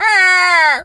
bird_vulture_croak_03.wav